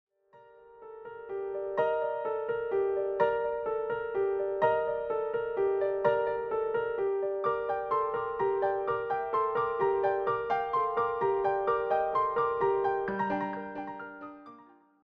piano arrangements centered on winter and seasonal themes
calm, steady piano pieces